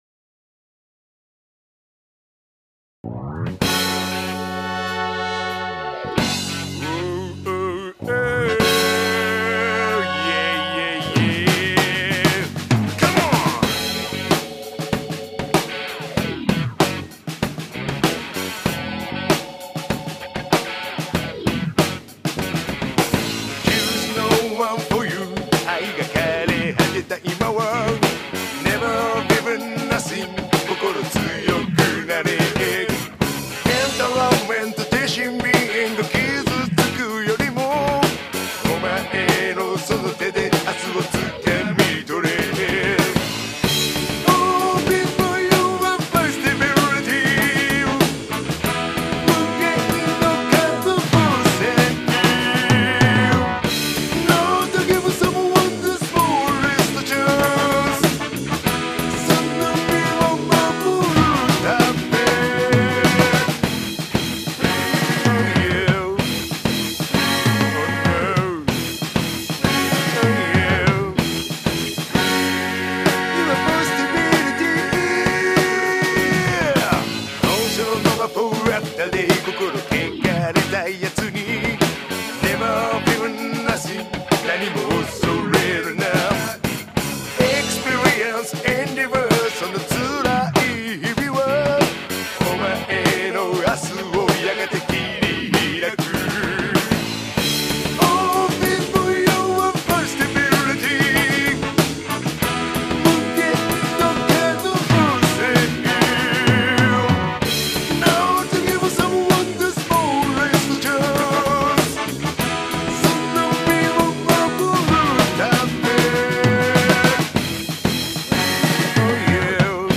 FUNK ナンバー!